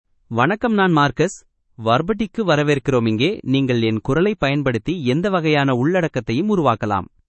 Marcus — Male Tamil AI voice
Marcus is a male AI voice for Tamil (India).
Voice sample
Male
Marcus delivers clear pronunciation with authentic India Tamil intonation, making your content sound professionally produced.